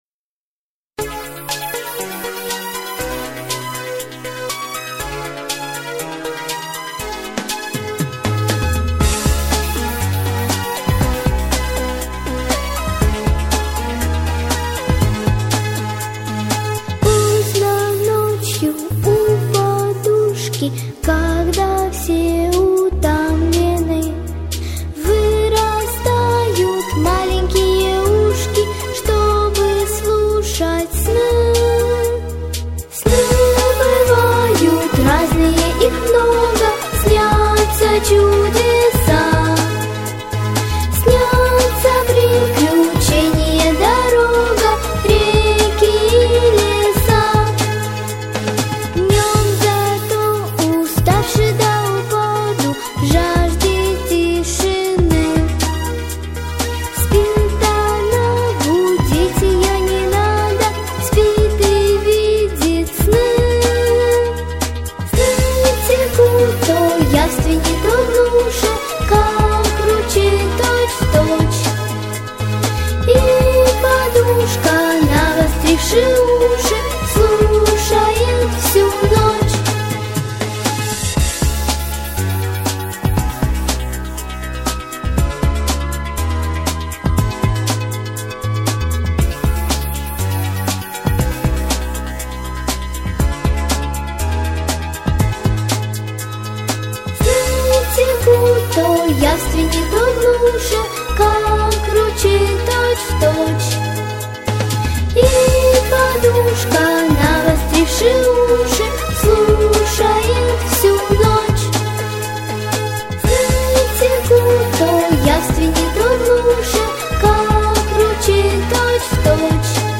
Детские песни